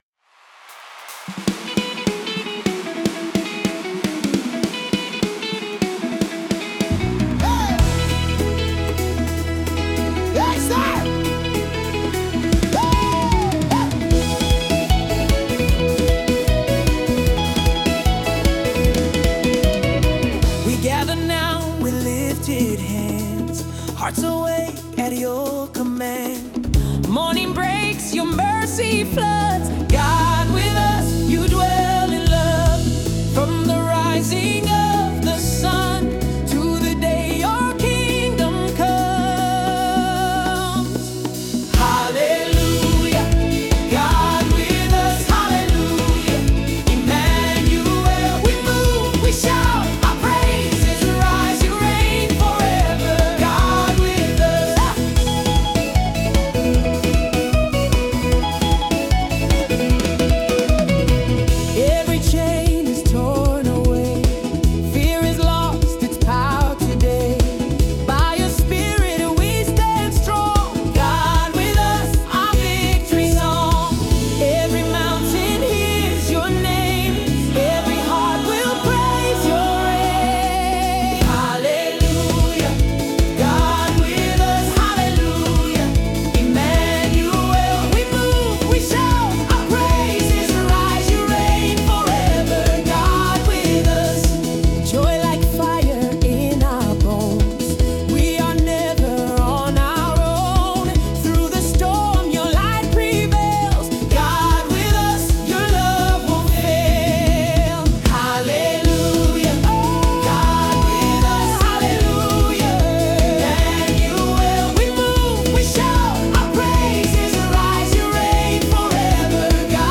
Through heartfelt lyrics and uplifting melodies